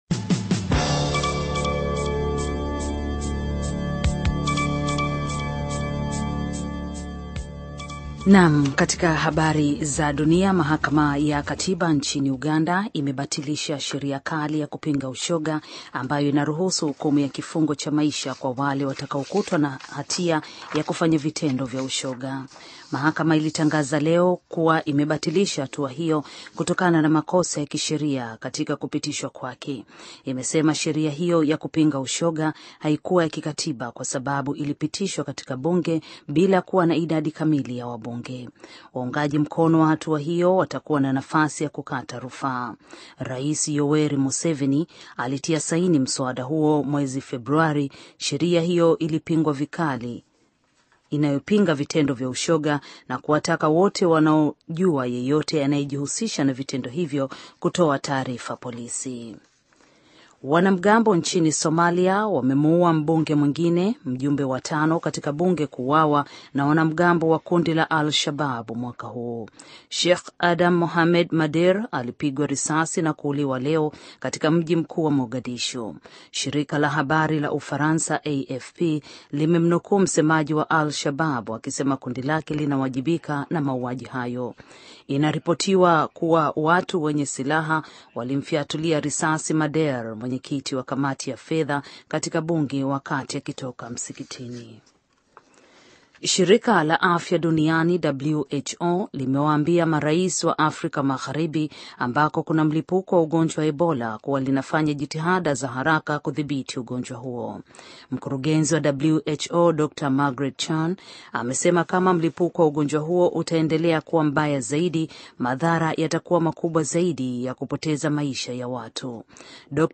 Taarifa ya Habari VOA Swahili - 5:11